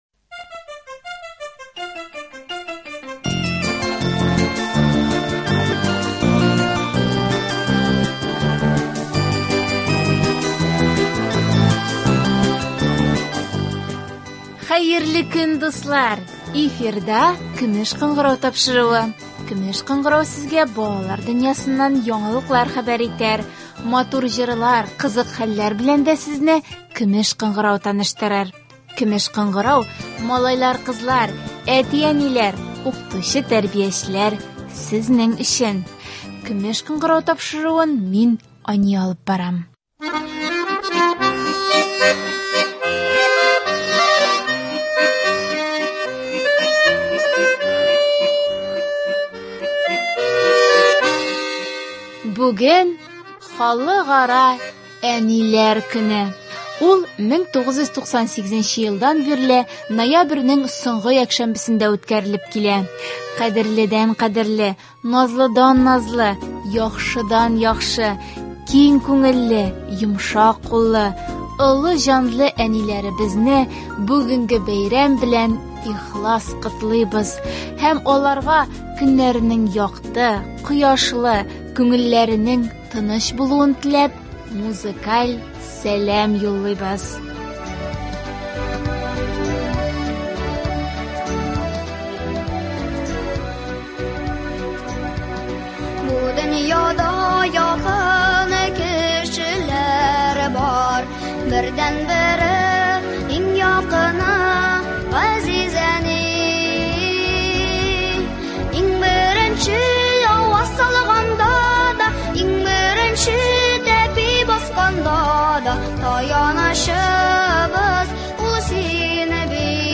Бүгенге тапшыруыбыз тулысы белән әниләребезгә багышлана. Балаларының котлаулары да, теләкләре дә матур җырлар белән үрелеп бара.